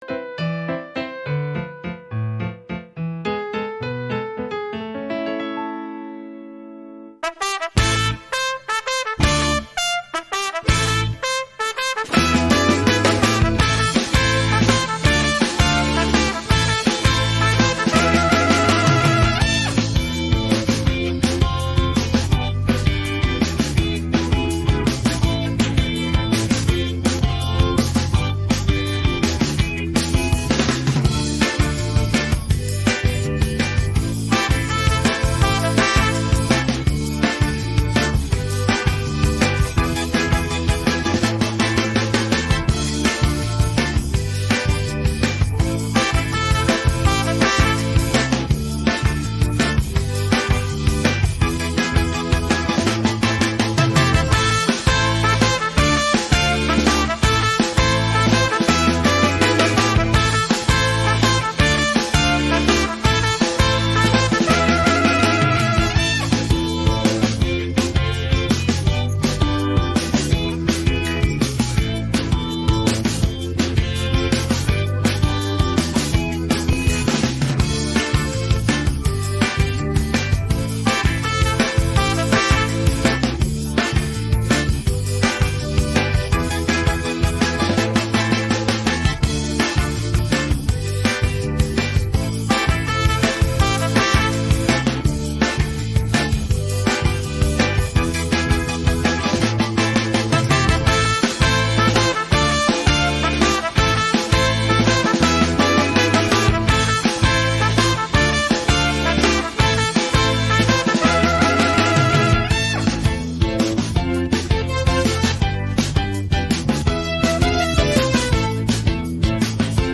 rock караоке